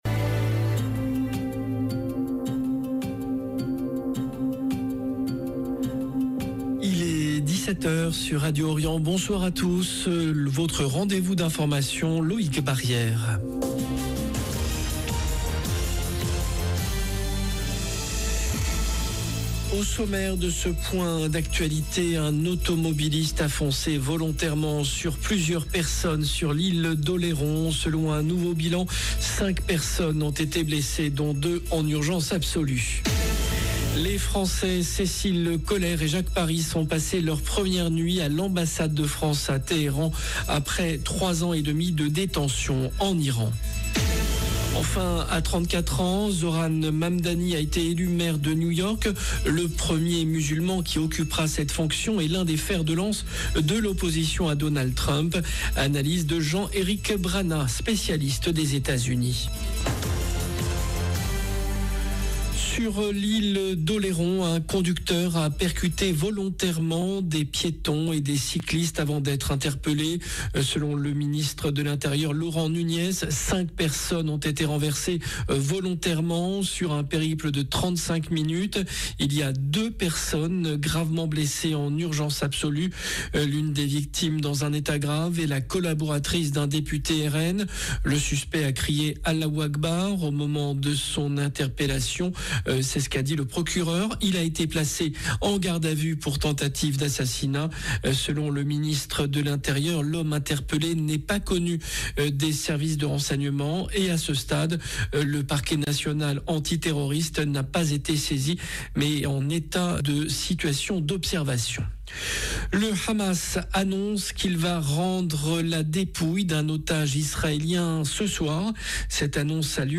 JOURNAL DE 17H